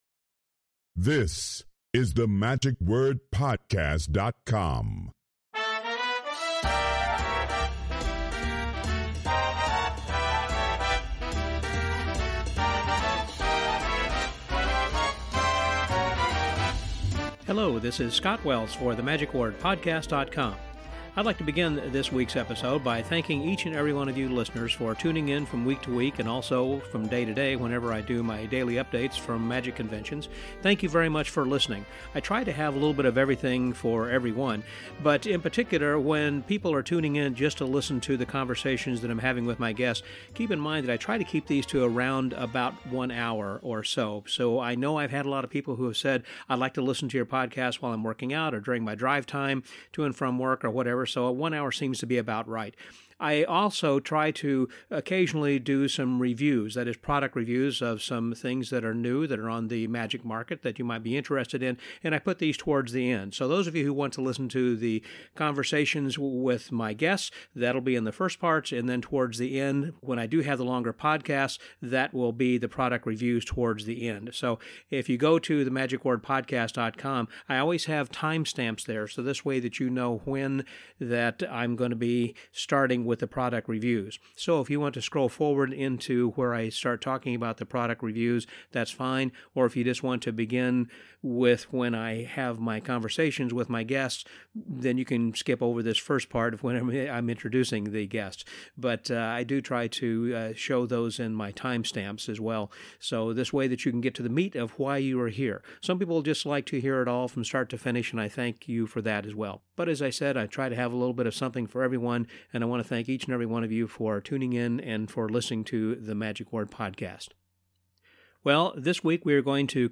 We sat down at the 2017 MAGIC Live! convention in Las Vegas for a wonderful chat about their experiences as a two-person mind reading duo.